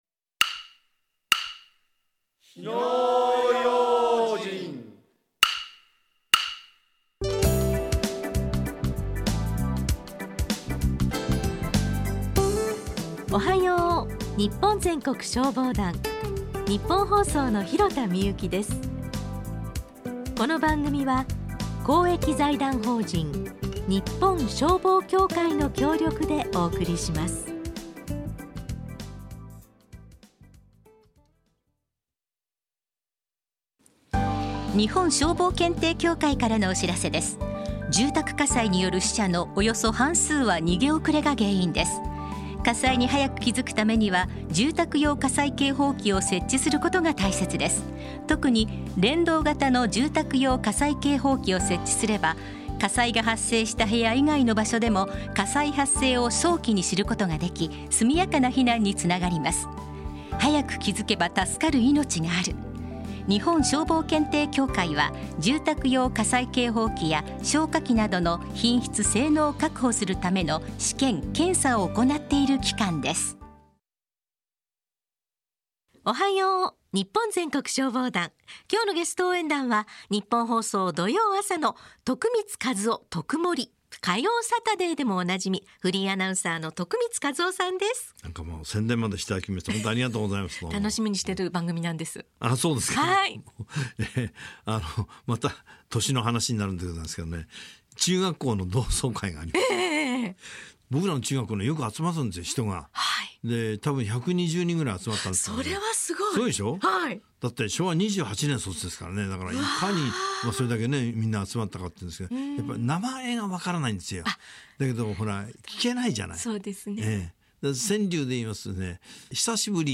ラジオ放送 - ＴＯＰ - 日本消防協会